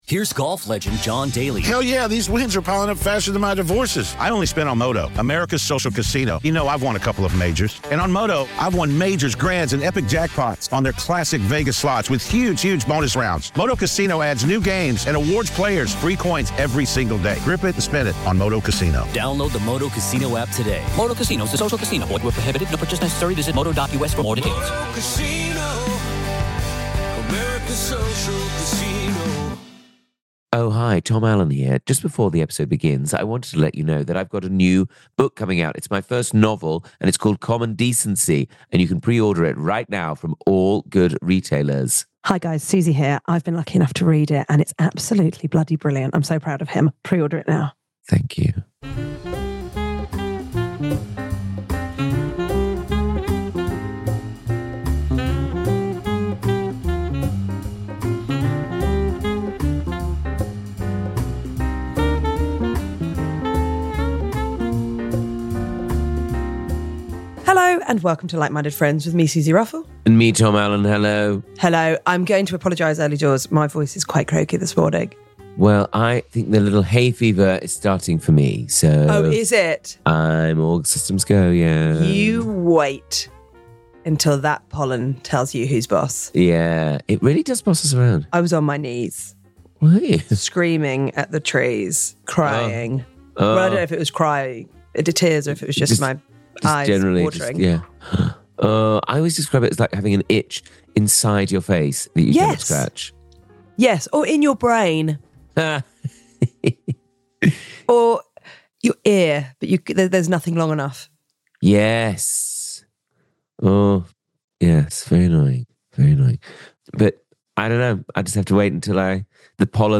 Comedians and dearest pals Tom Allen and Suzi Ruffell chat friendship, love, life and culture...sometimes...